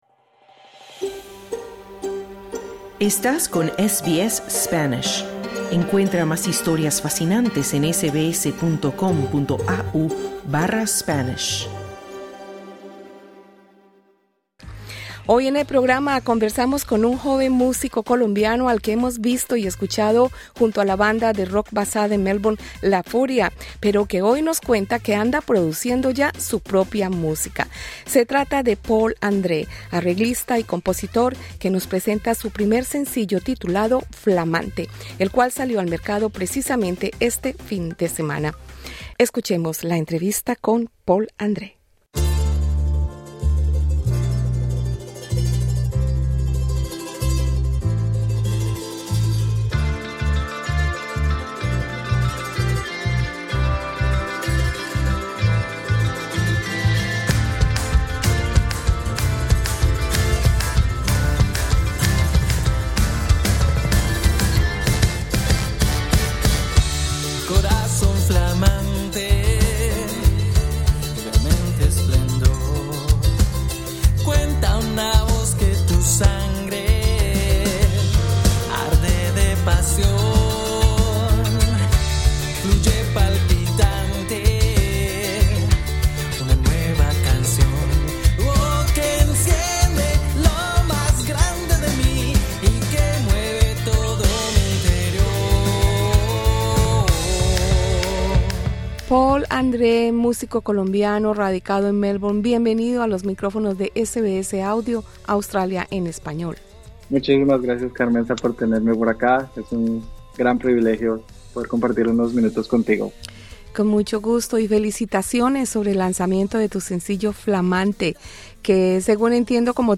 SBS en español
En conversación con Australia en español, de SBS Audio